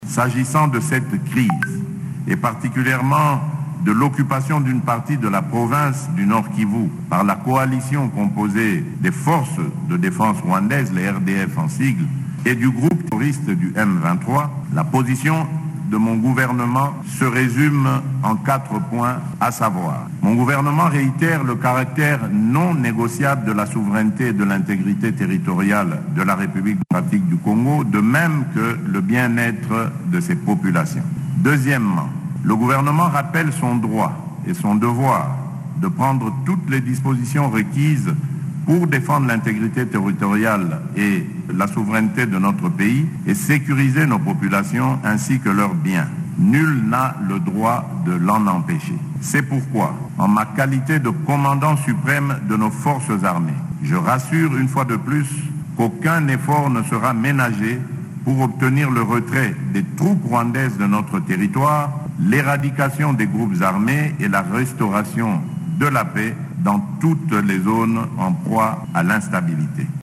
« Aucun dialogue ne peut et ne pourra intervenir avec notre agresseur tant qu’il occupera, quelle qu’en soit l’étendue, une portion de notre territoire », a indiqué mardi 30 janvier le Président Félix-Antoine Tshisekedi lors de la traditionnelle cérémonie d’échange des vœux avec le corps diplomatique accrédité en République Démocratique du Congo.